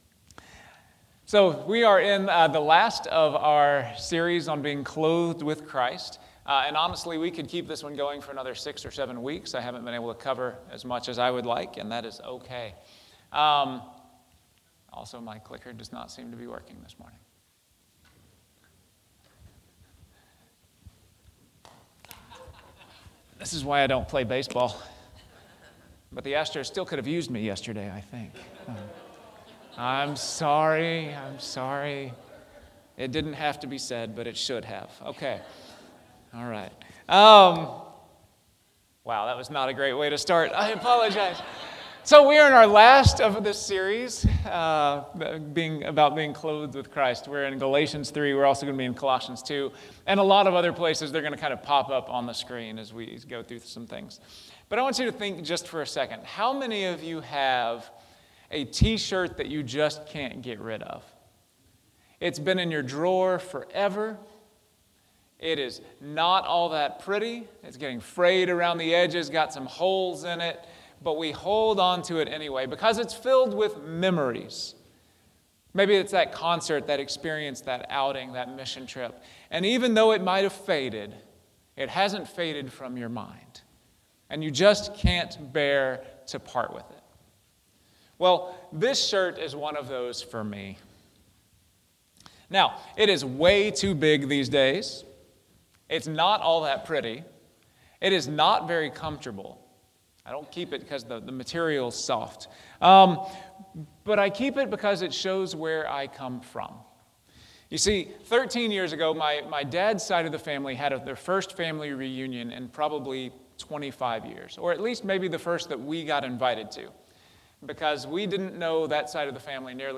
Weekly Sermon Audio